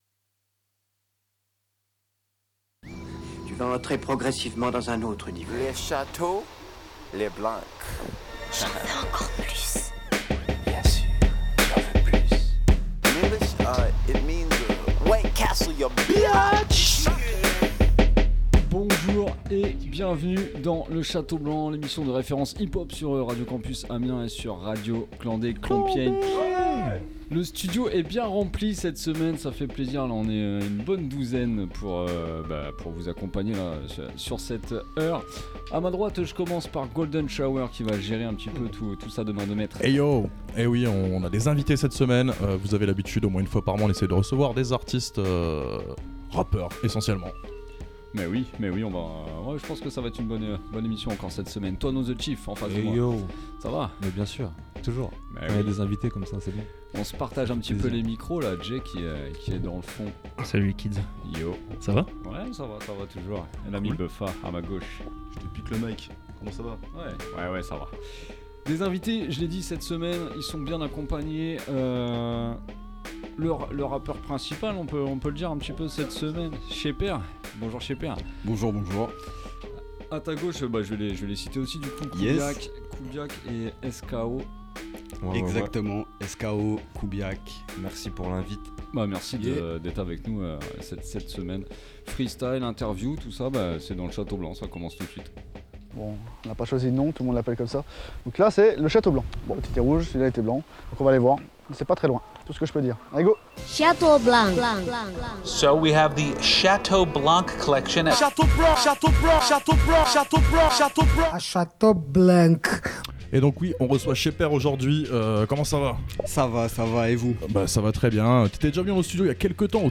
Interview et freestyle
Selecta actu Hip hop